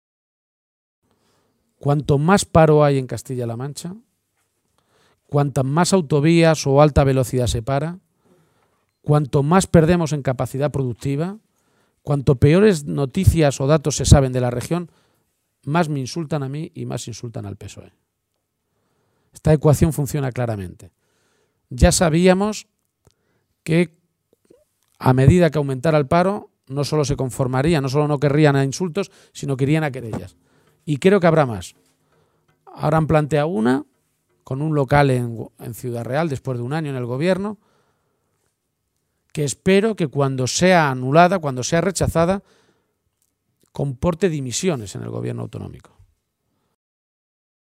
A preguntas de los medios de comunicación, ha confirmado que el PSOE de Castilla-La Mancha va a iniciar acciones judiciales contra el secretario general del PP regional, Vicente Tirado, que acusó ayer, durante la clausura del Congreso autonómico popular, a los socialistas de cometer delitos al haberse llevado el dinero.